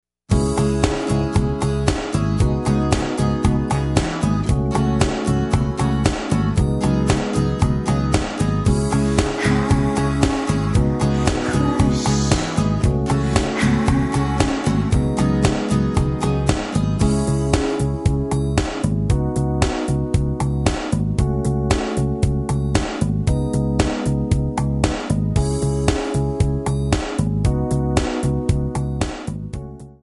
D
MPEG 1 Layer 3 (Stereo)
Backing track Karaoke
Pop, 1990s